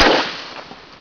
dryfire.wav